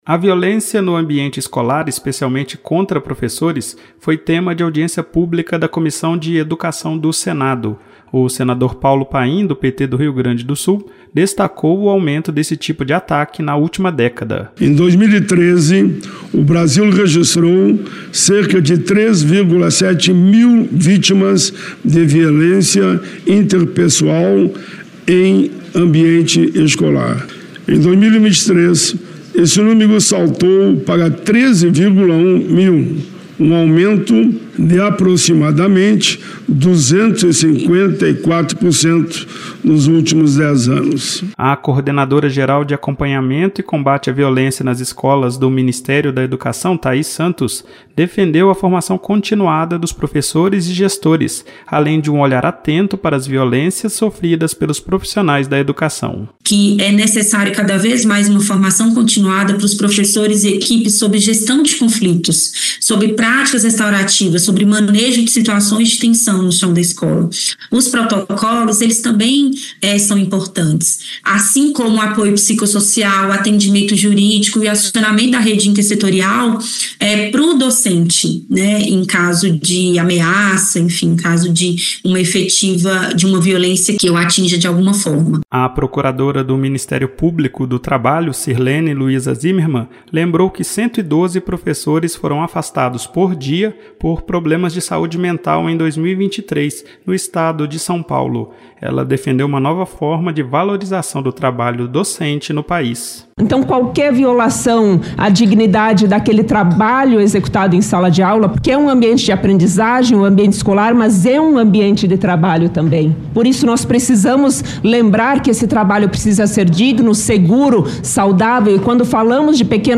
Audiência na Comissão de Educação debate violência contra professores